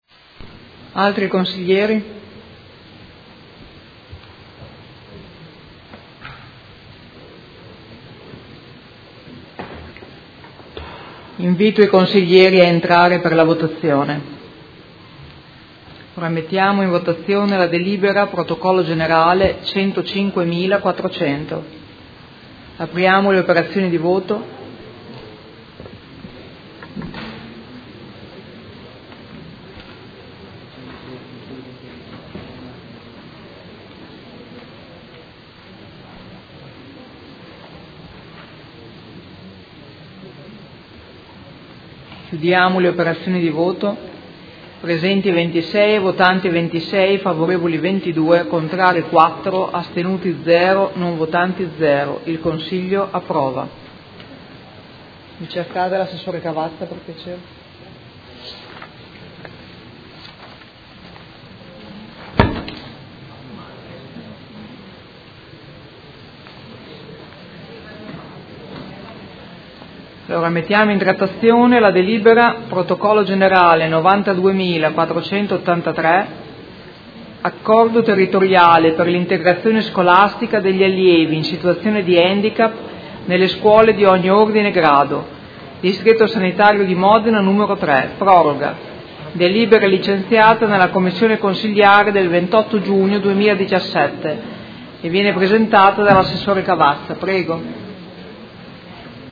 Presidentessa — Sito Audio Consiglio Comunale
Seduta del 20/07/2017 Mette ai voti.